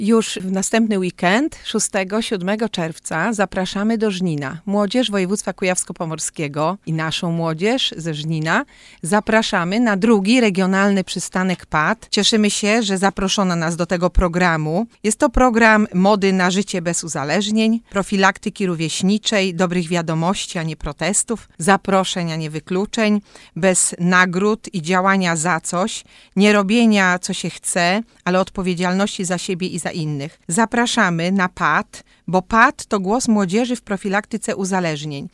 Zapraszała wiceburmistrz Żnina Aleksandra Nowakowska.